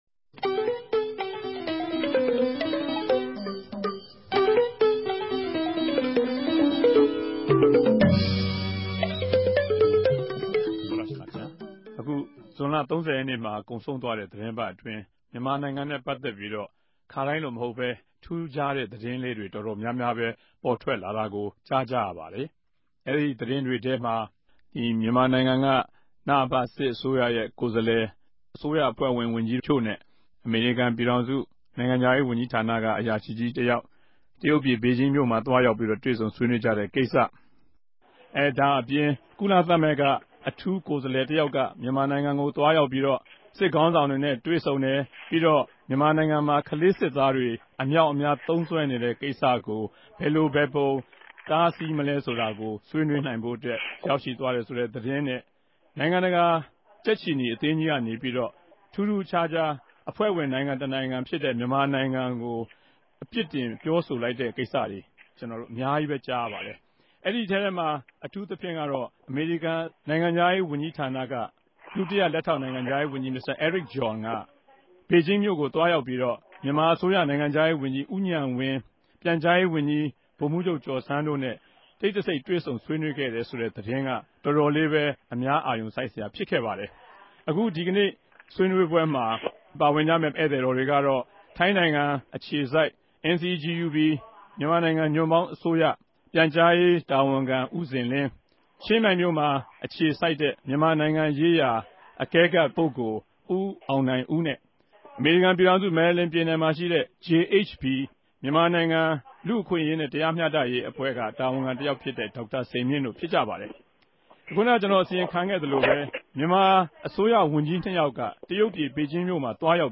တနဂဿေိံြ ဆြေးေိံြးပြဲစကားဝိုင်း